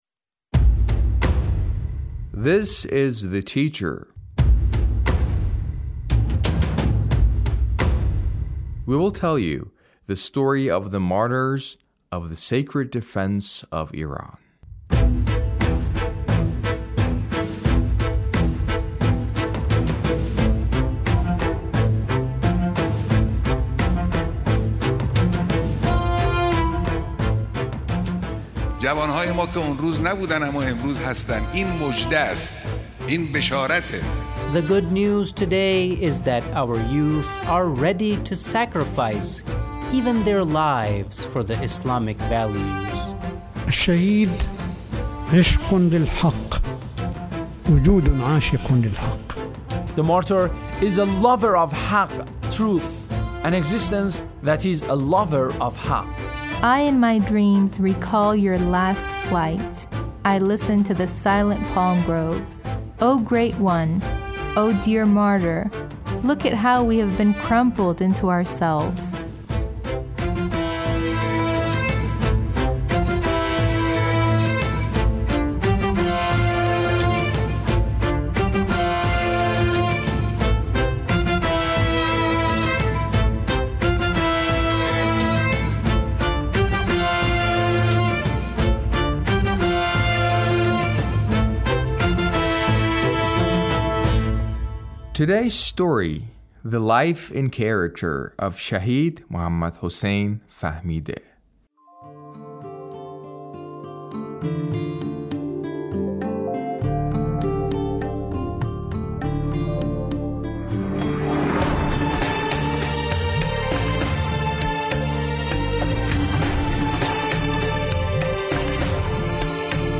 A radio documentary on the life of Shahid Muhammad Hussain Fahmideh